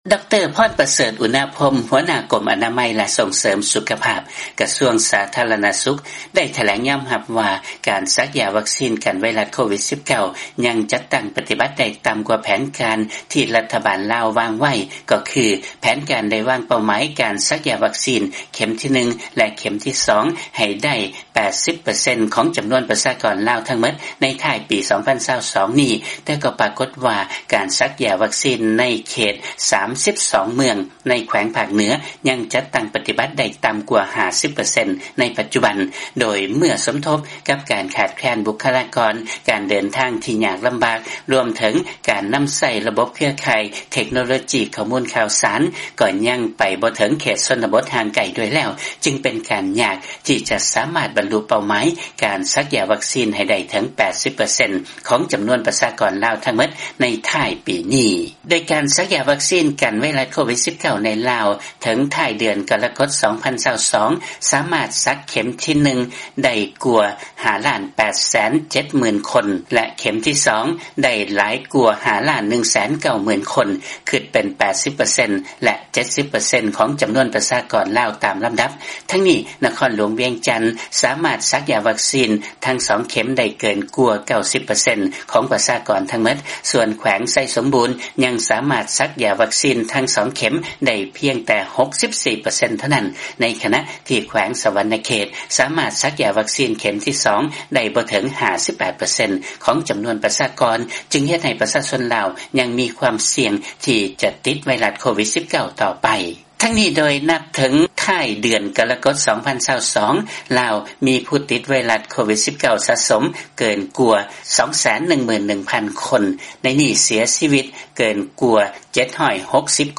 ມີລາຍງານຈາກບາງກອກ